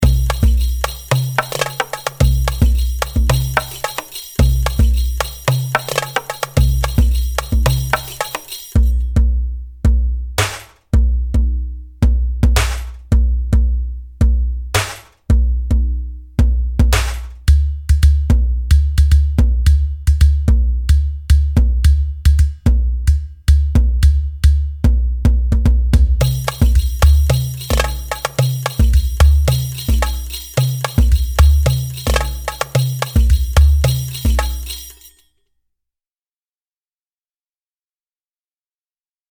A ready-to-use virtual instrument featuring authentic Turkish percussion. With realistic articulations, and anatolian grooves.
Turkish-Drum-Solo.mp3